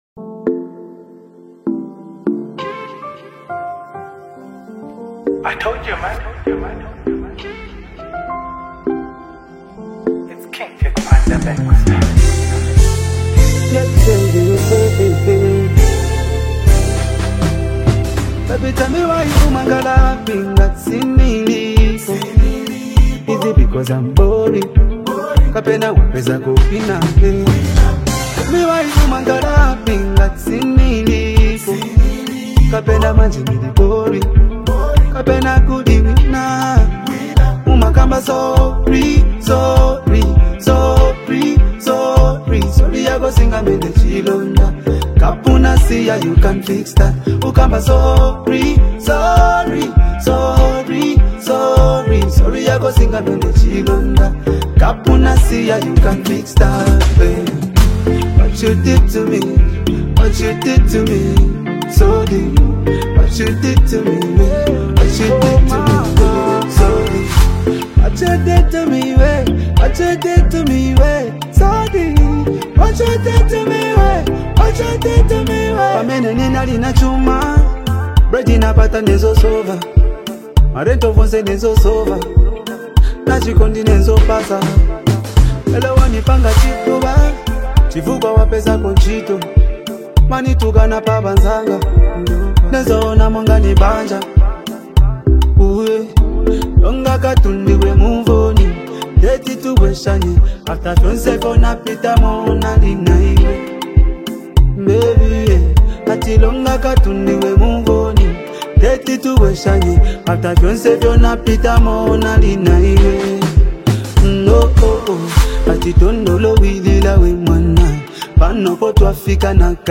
blending catchy melodies with meaningful songwriting.
Genre: Afro-Beats